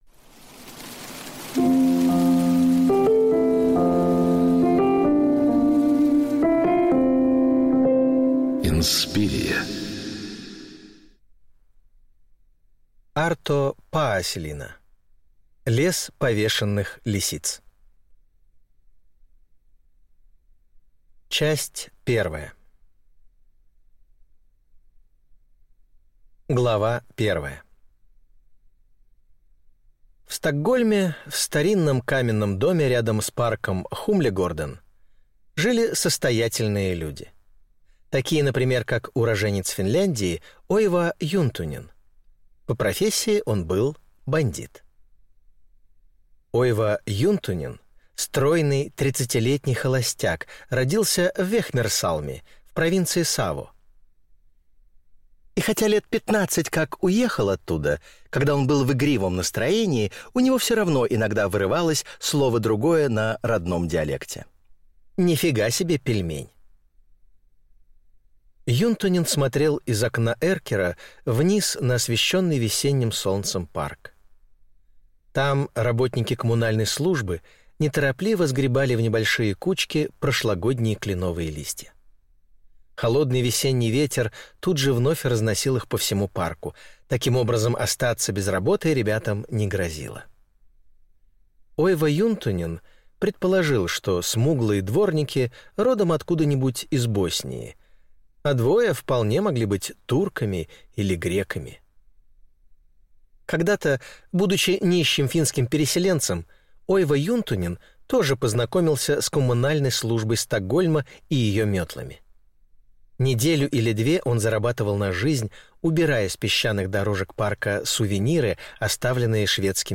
Аудиокнига Лес повешенных лисиц | Библиотека аудиокниг